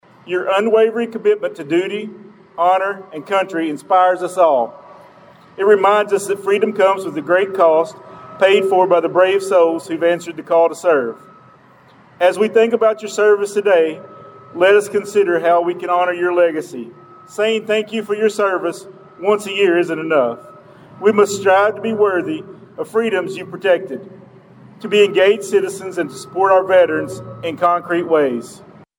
In November, Princeton Mayor Brock Thomas spoke at the Veterans Day program on the Caldwell County Courthouse steps, emphasizing the day as a solemn reminder of the sacrifices made by our Armed Forces.